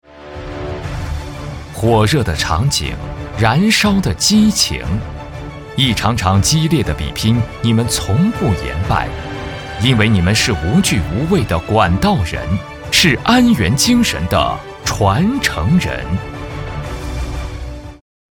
男11号配音师 点击进入配音演员介绍 进入后下滑播放作品集↓↓↓ 配音演员自我介绍 A级配音师，声歌， 2001年毕业于音乐学院声乐专业，本科学历 毕业后从事地方台广播播音，辞职后一直从事声音工作至今已有16年 累计播读稿件量超过1亿字。
代表作品 Nice voices 颁奖 广告 解说 旁白 专题片 宣传片 纪录片 颁奖-男11-公司.mp3 复制链接 下载